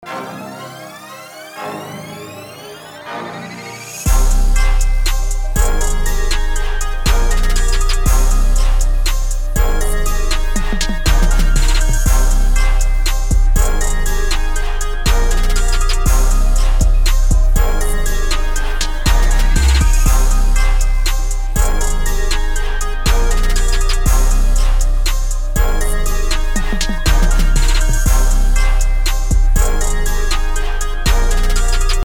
BPM: 120 BPM
Key: Eb Major
Preview del beat: